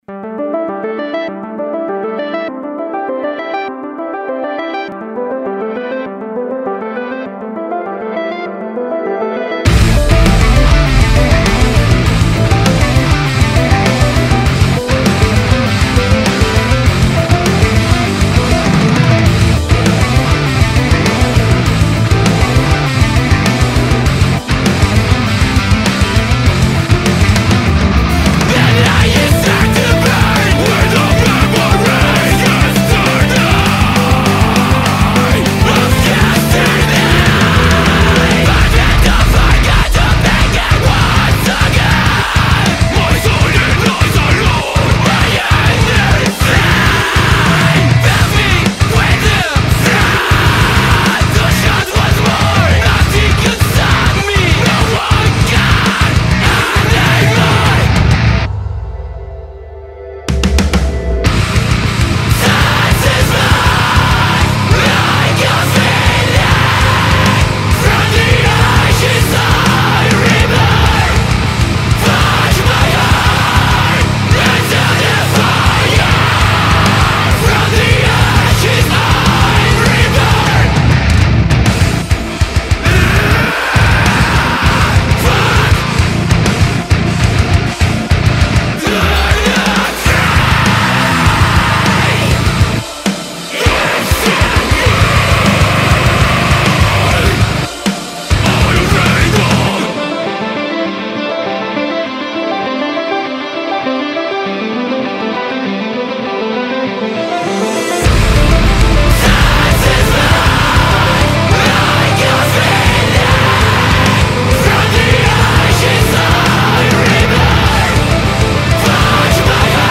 Deathcore / Metalcore / Hardcore
Bajo
Guitarra
Vocalista
Batería